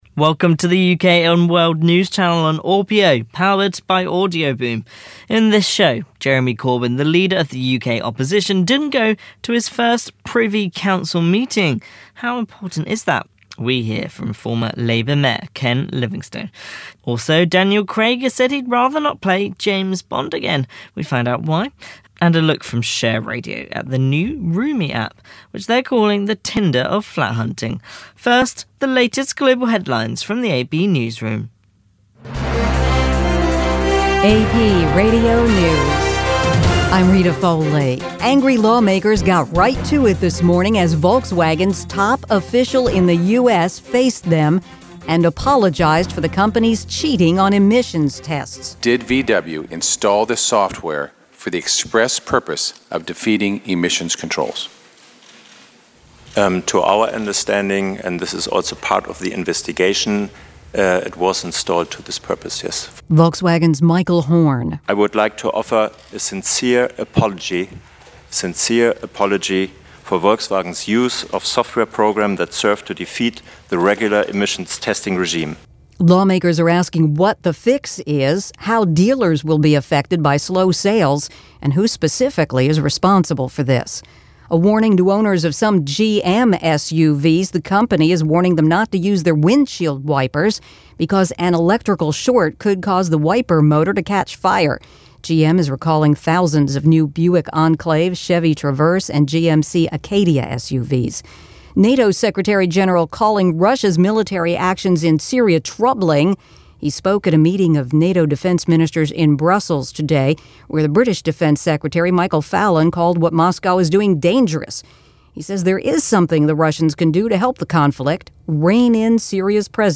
The latest UK and World News, powered by audioBoom